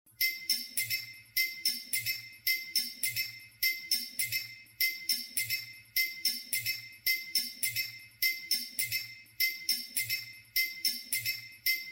Tiếng gõ của người bán hủ tiếu, mì gõ Sài Gòn
Thể loại: Tiếng động
Description: Download tiếng gõ leng keng, lách cách, cốc cốc vang lên giữa đêm Sài Gòn (mẫu số 3), âm thanh quen thuộc của người bán hủ tiếu, mì gõ bình dân. Tiếng gõ ấy vang vọng giữa con hẻm nhỏ, hòa vào hơi nước nghi ngút của nồi nước lèo, gợi nhớ bao kỷ niệm thân thương.
tieng-go-cua-nguoi-ban-hu-tieu-mi-go-sai-gon-www_tiengdong_com.mp3